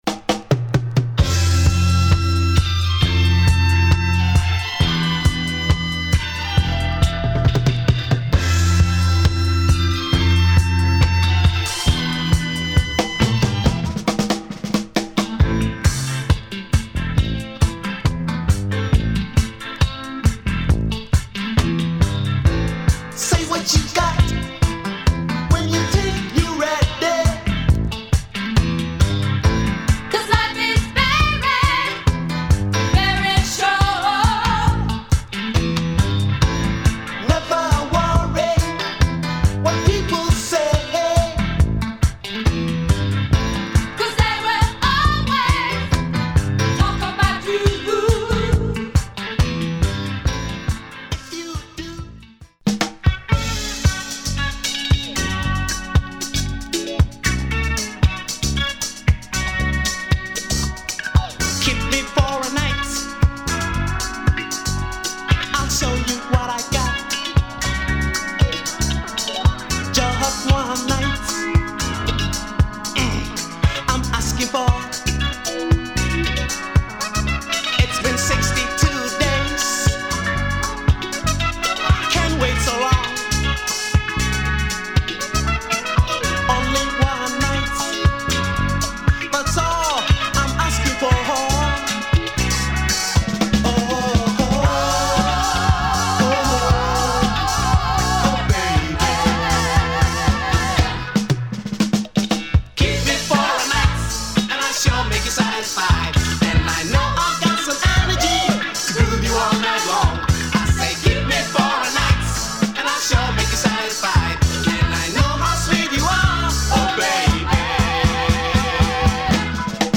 Afro disco
plus some African reggae tunes.